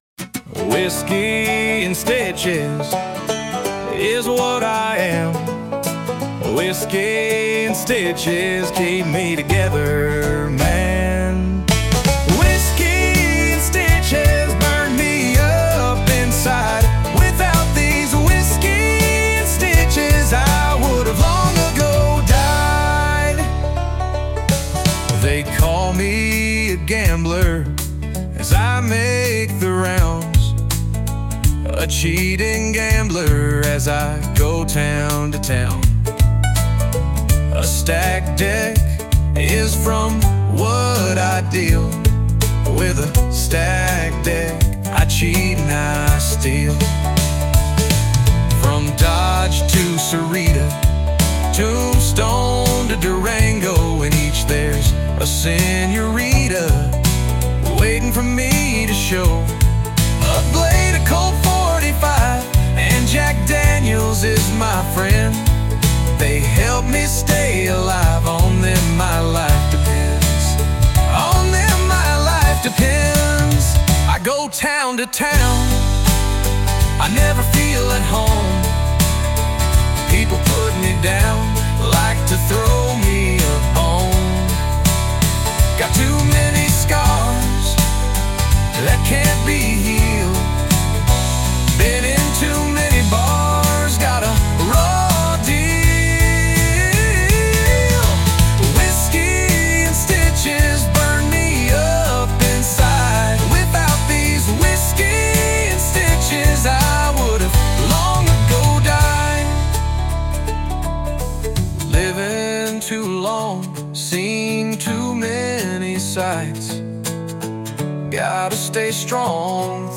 gritty and melancholic cowboy ballad